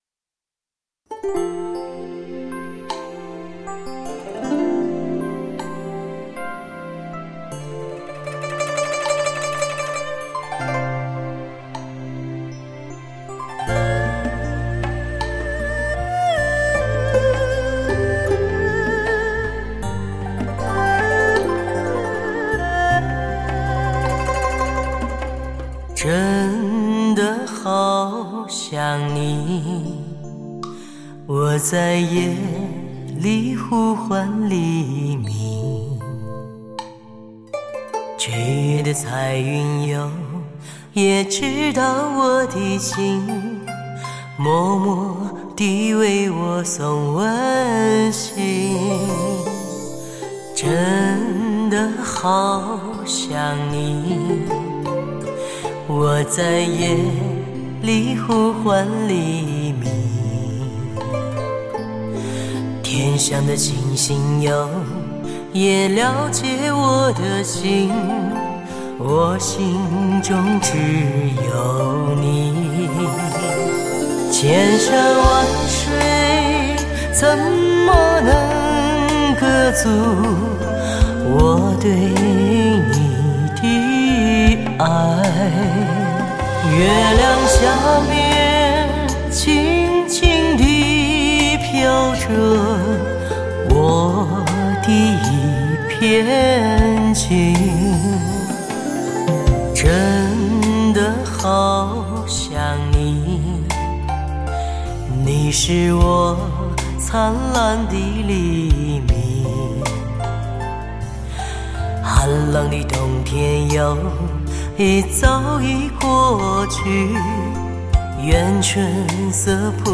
中国独特女低音